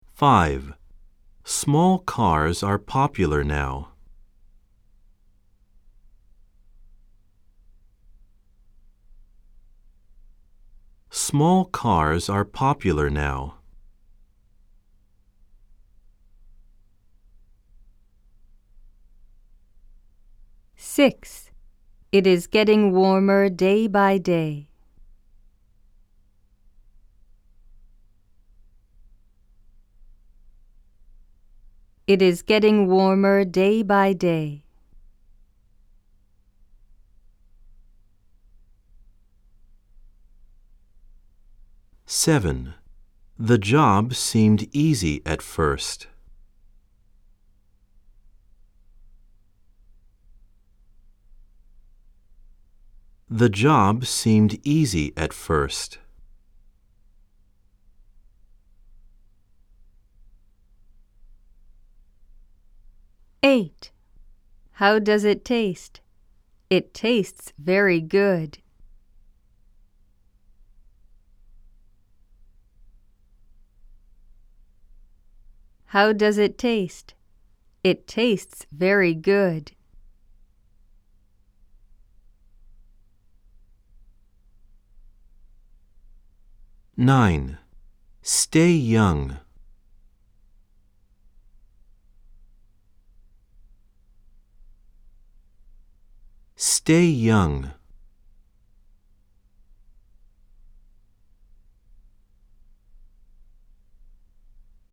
（3）暗唱例文100　各章別ファイル（英文2回読み）
※（1）（2）では英文のあとに各5秒のポーズ、（3）（4）では各7秒のポーズが入っています。